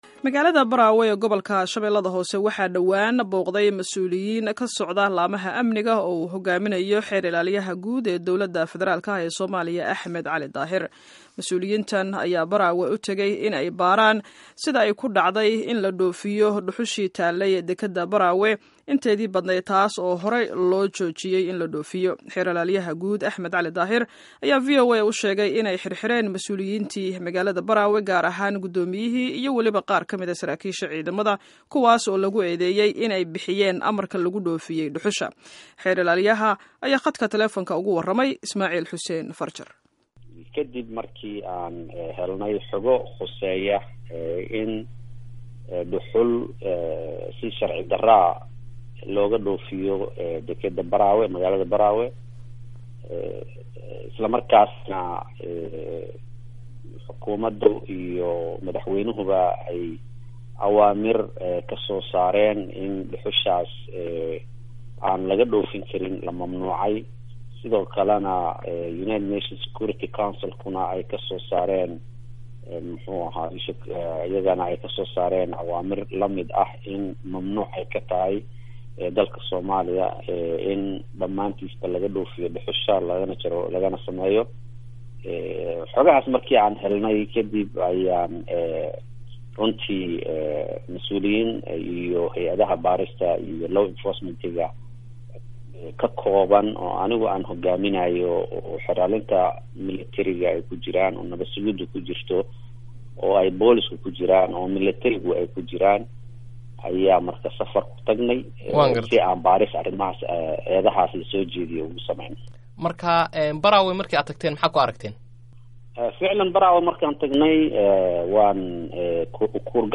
Dhageyso wareysiga Xeer Ilaaliyaha